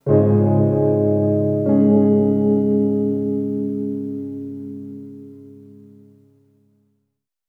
Treated Piano 06.wav